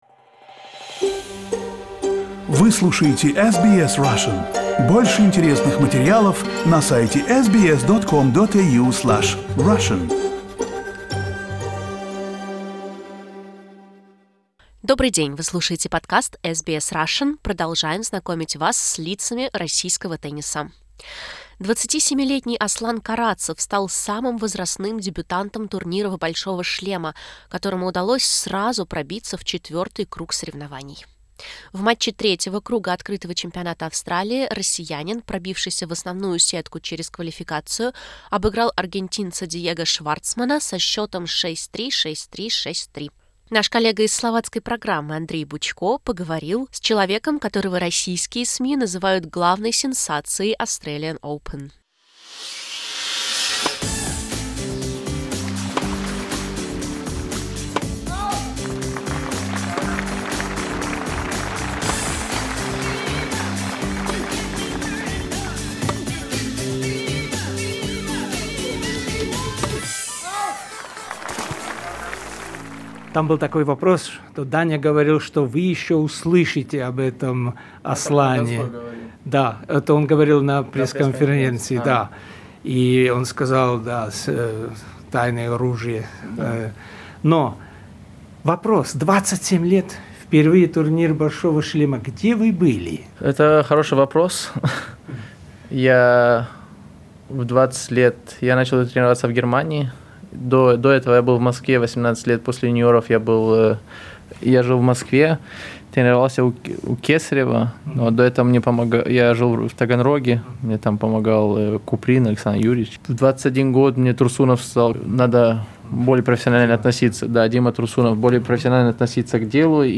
Exclusive interview in Russian only.